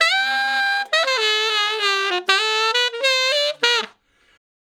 066 Ten Sax Straight (D) 39.wav